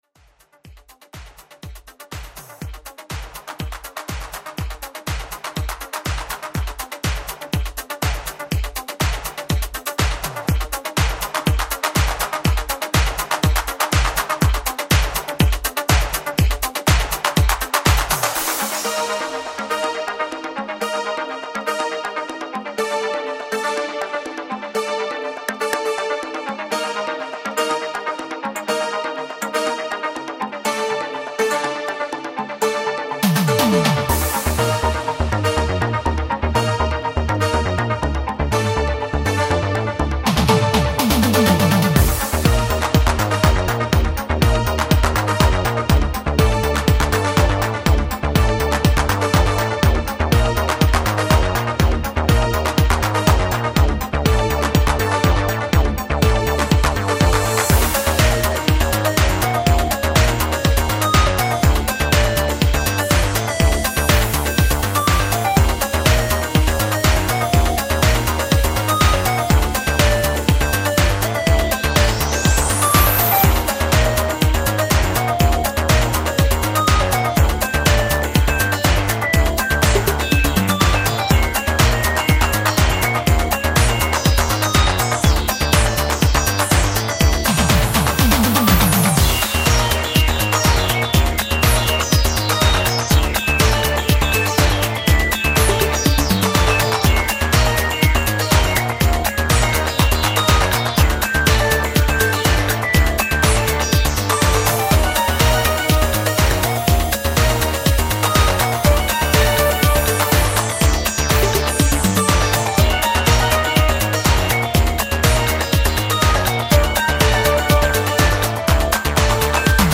Italo esque cover